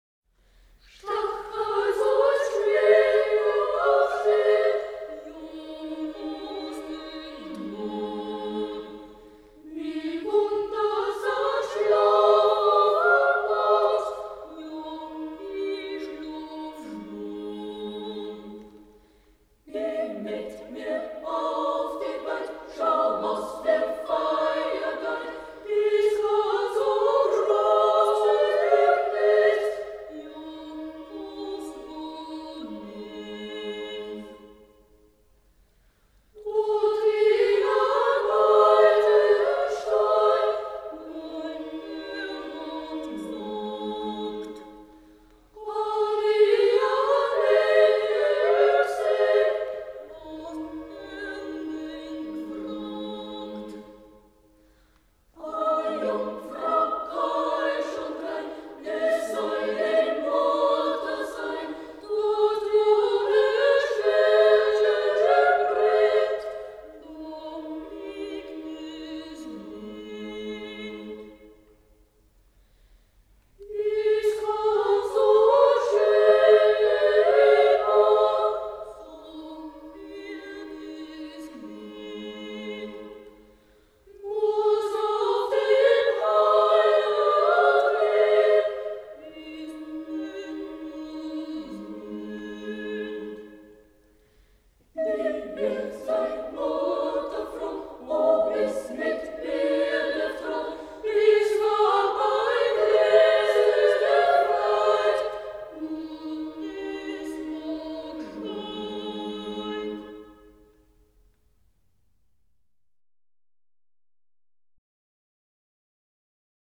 ist ein Hirtenlied aus der Steiermark
is a shepherd's carol rom Styria